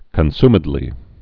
(kən-smĭd-lē)